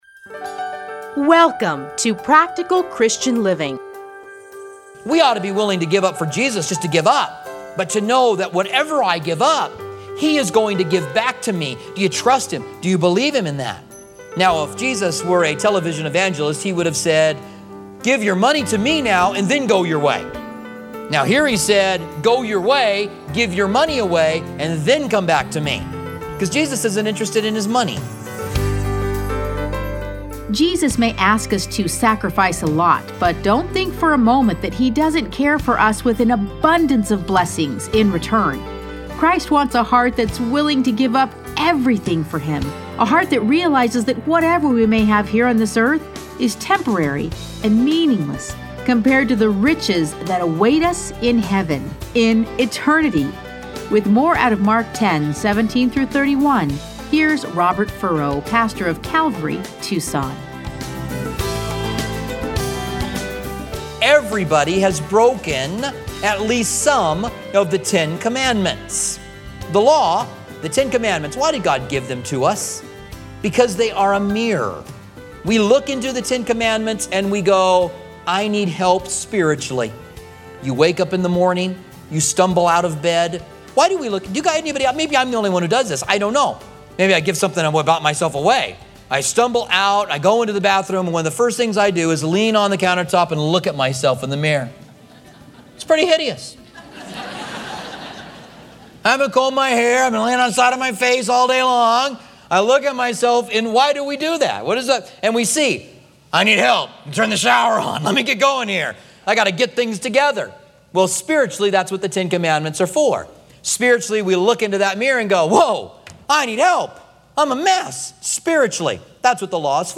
Listen to a teaching from Mark 10:17-31.